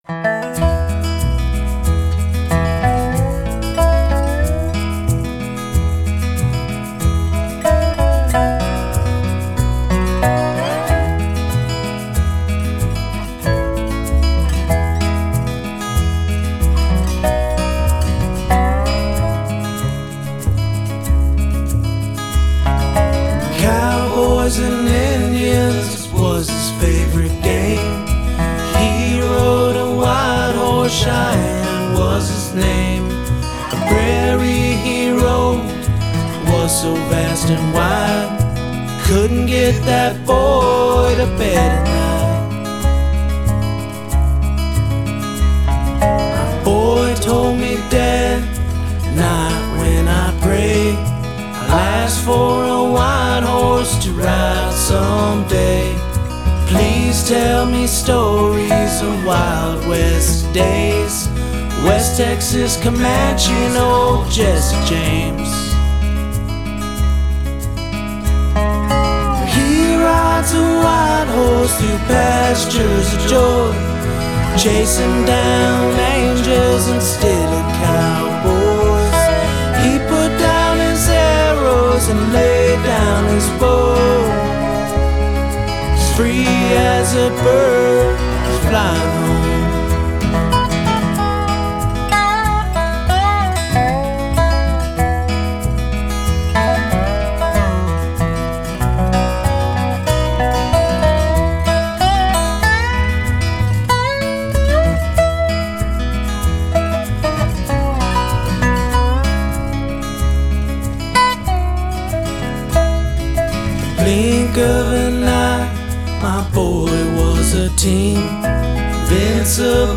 This is Texas Americana from an Iraqi war hero.